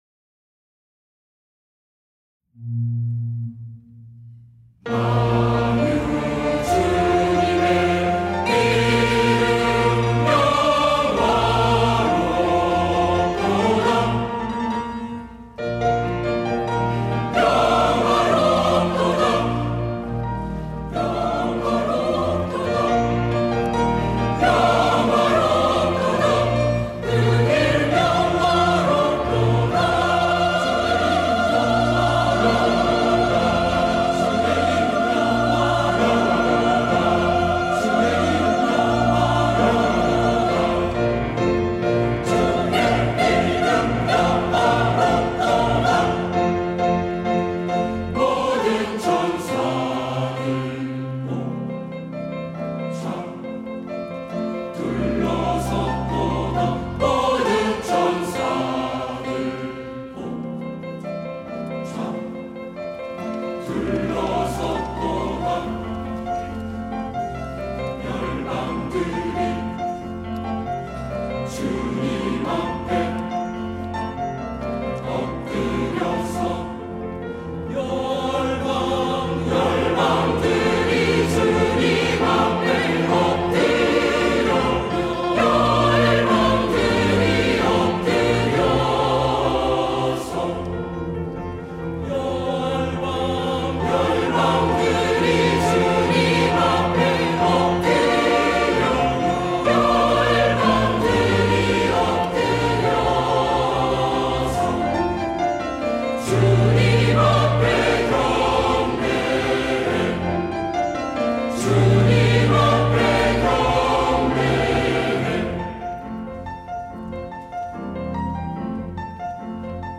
시온(주일1부) - 영화롭도다
찬양대